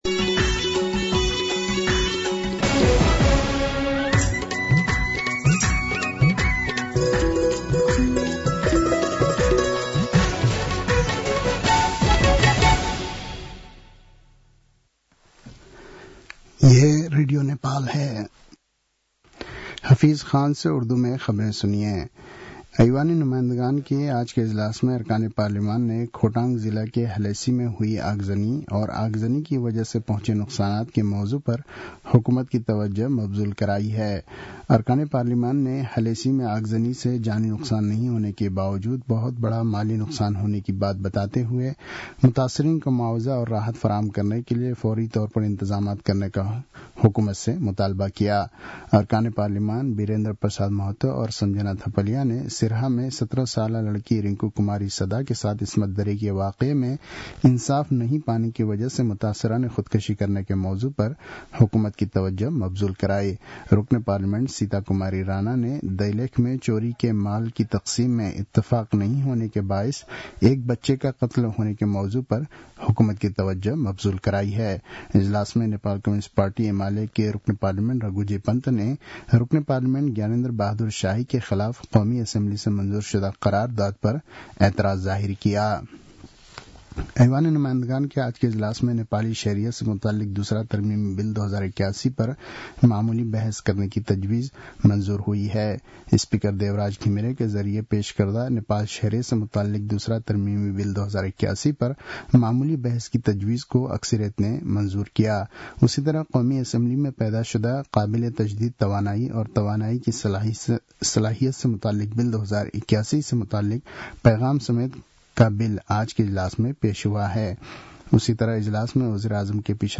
An online outlet of Nepal's national radio broadcaster
उर्दु भाषामा समाचार : ६ फागुन , २०८१
Urdu-news-11-05.mp3